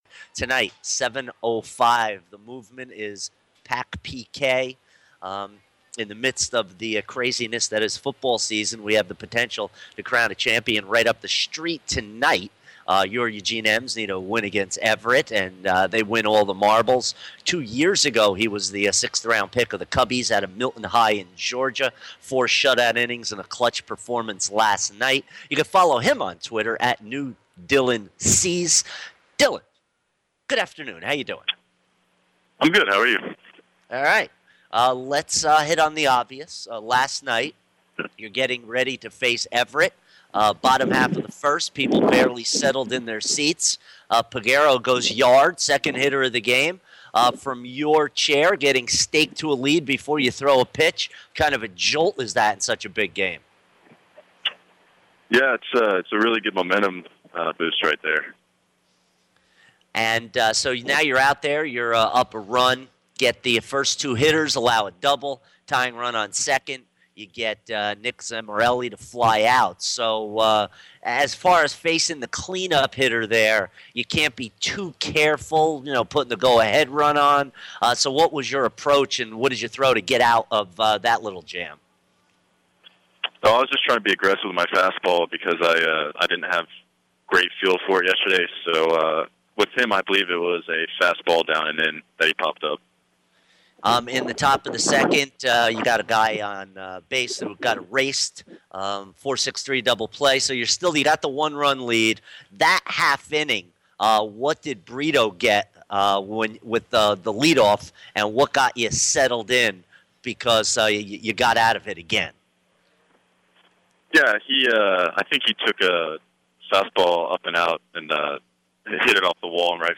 Dylan Cease Interview 9-12-16